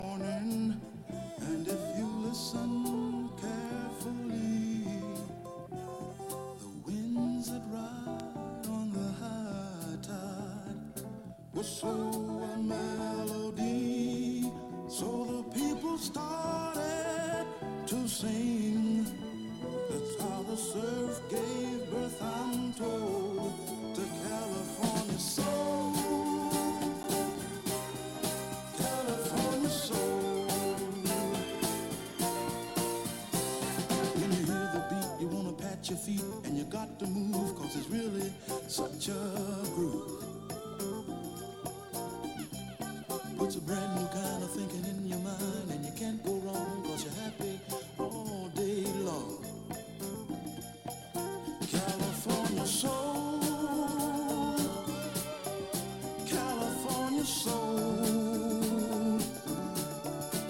psychedelic soul